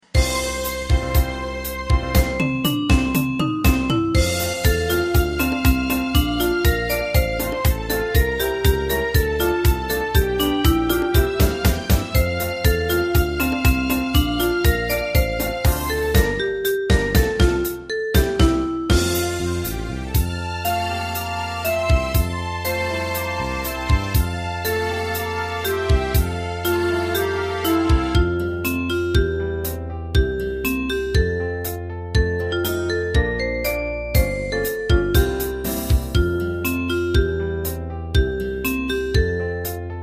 大正琴の「楽譜、練習用の音」データのセットをダウンロードで『すぐに』お届け！
カテゴリー: ユニゾン（一斉奏） .